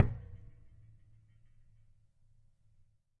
乐器是由金属弹簧从一个大的卡拉巴什壳上延伸出来的；录音是用一对土工话筒和一些KK;接触话筒录制的，混合成立体声。 动态用pp（软）到ff（大）表示；名称表示记录的动作。
标签： 低音 低金属制品 冲击 弹簧 木材
声道立体声